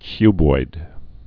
(kyboid)